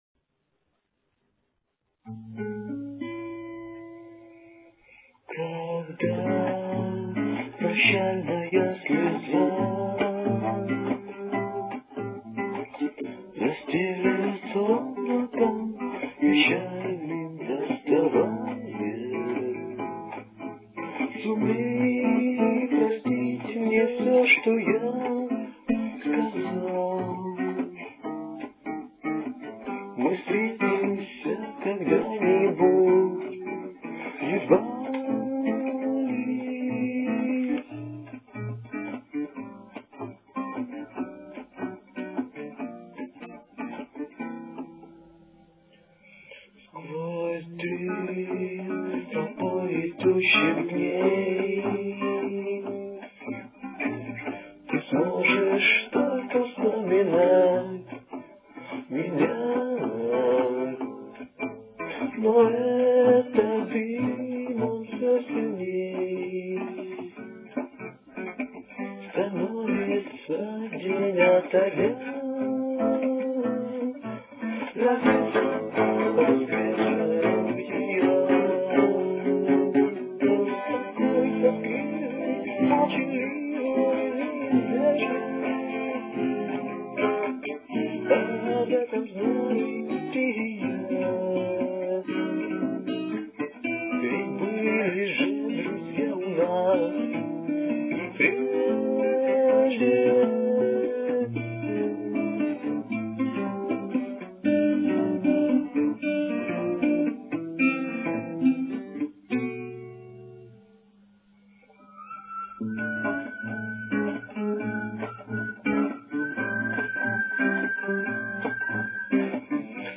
Old recording of original
All verses are in soft bosa-nova style (a-la A.-C. Jobim) with acoustic
guitar, except Refr which should be played with lead drive guitar.